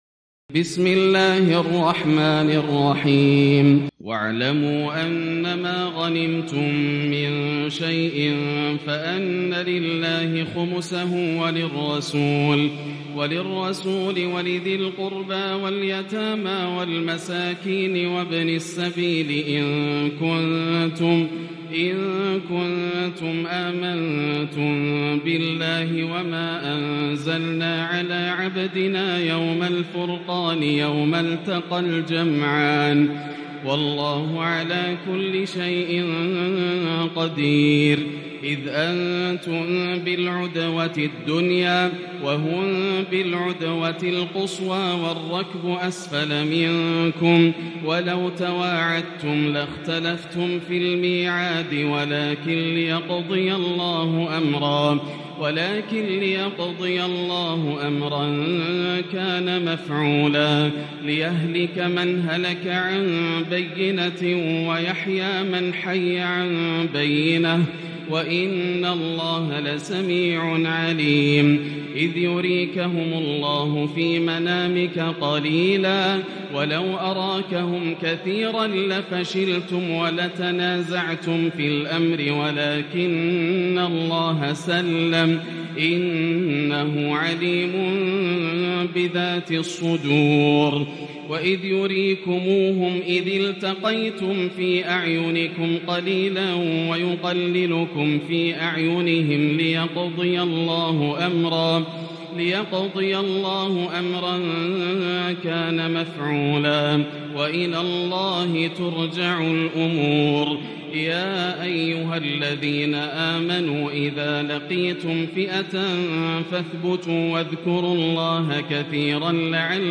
الجزء العاشر > مصحف الشيخ ياسر الدوسري (مصحف الأجزاء) > المصحف - تلاوات ياسر الدوسري